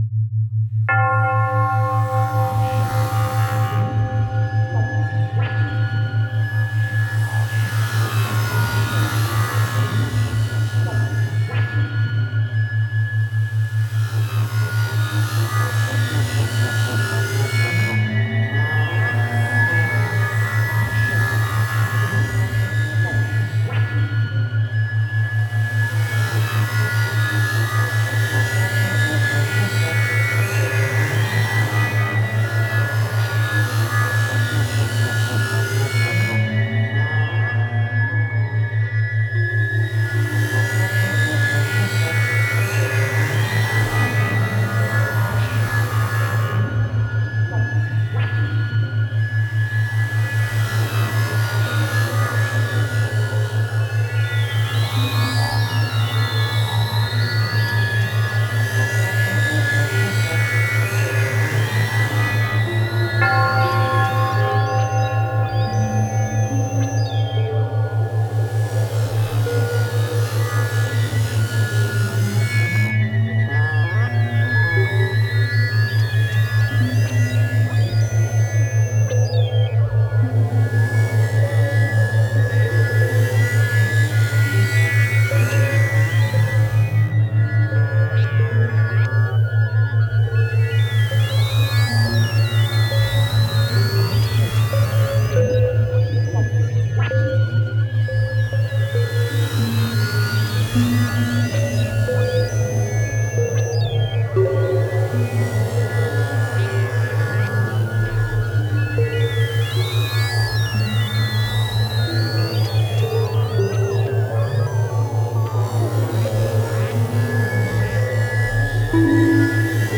ある種のミニマル・アンビエント系の様相でありながら、総時間189分（3x3x3x3x7）をかけて、
更にこれら42曲は、特別な音響的デザインにより、ディープなバイノーラル・ビーツ体験をもたらす。
バイノーラル・ビーツの為の正弦波と、教会の鐘の音のサンプリングを除き、
総ての音はギター起源で録音されている。